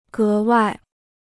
格外 (gé wài): especially; particularly.